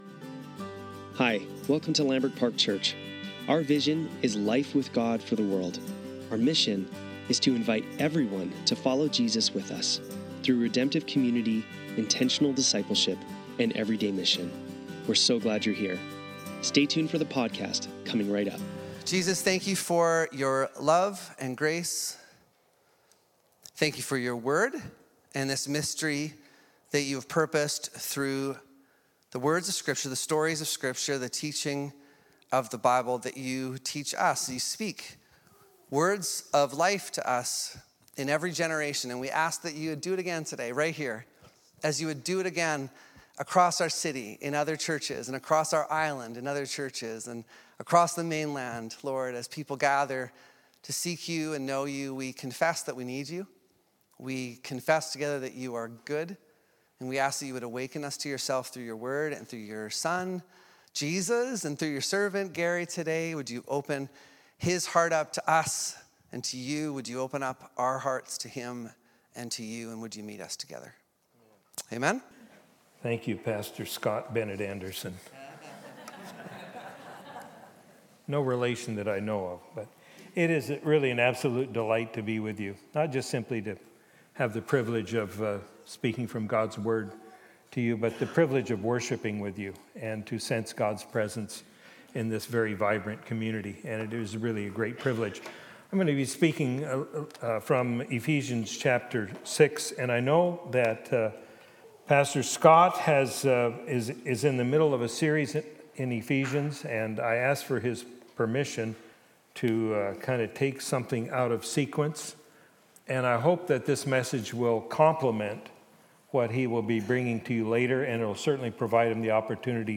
Sunday Service - January 12, 2025